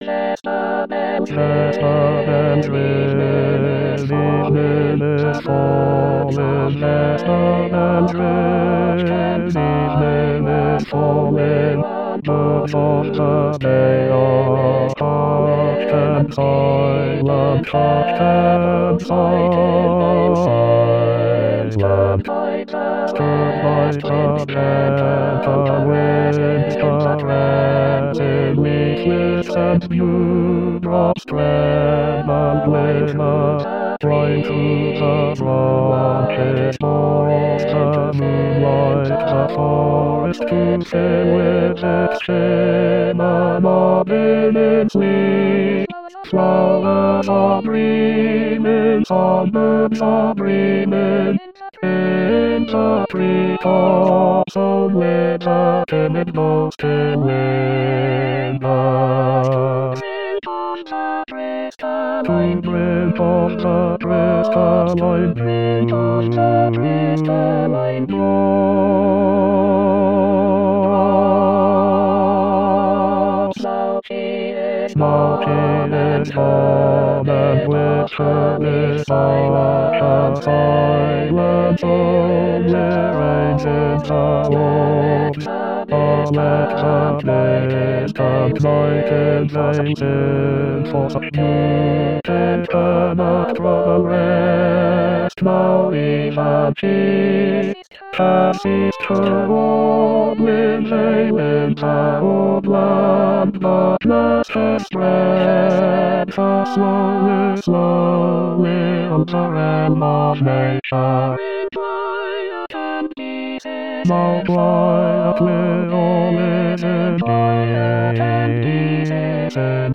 Bass Bass 1